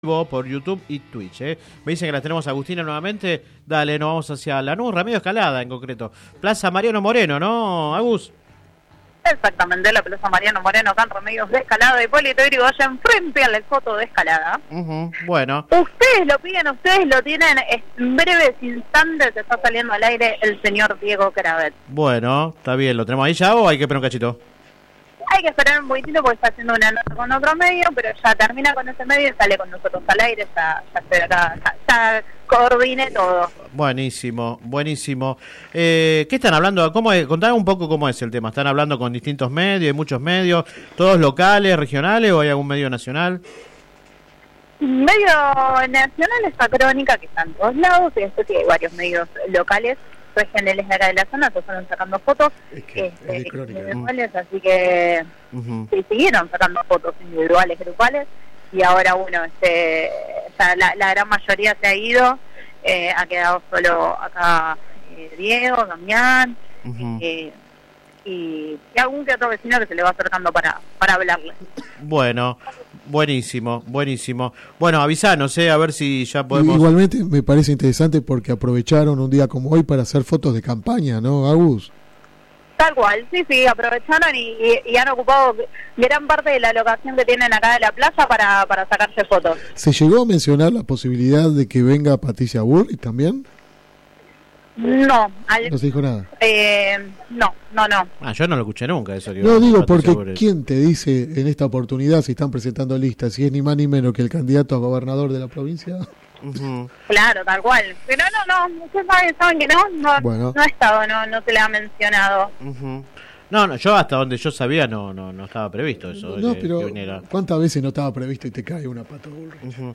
En el marco de la presentación formal de los precandidatos de la lista de Patricia Bullrich en Lanús, el precandidato a intendente Diego Kravetz habló en el programa radial Sin Retorno (lunes a viernes de 10 a 13 por GPS El Camino FM 90 .7 y AM 1260). El aspirante a suceder a Néstor Grindetti habló de la campaña, el armado nacional y local.
Click acá entrevista radial